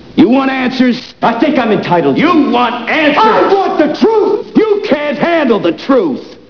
Jack blowing up in "A Few Good Men" (71K)